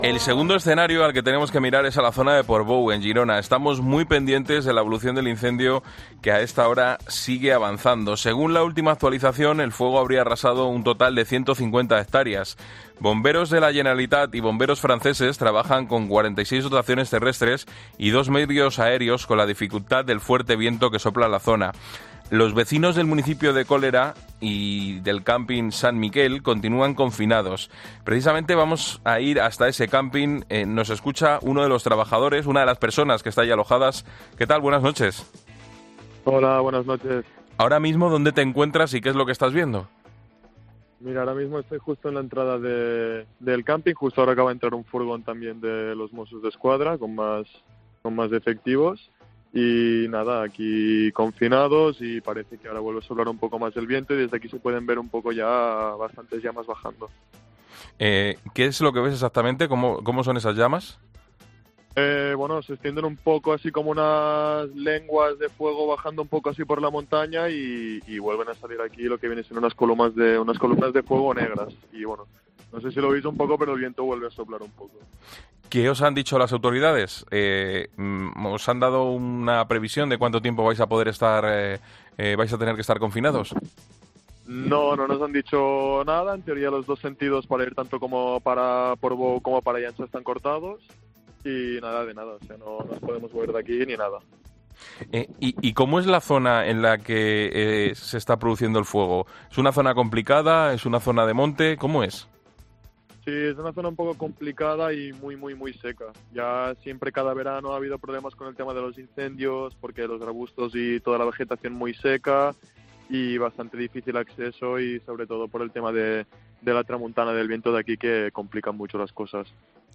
En La Linterna de COPE lo explicaba uno de los trabajadores del campamento, "tenemos a la gente confinada y controlamos que la gente esté dentro de los bungaloes".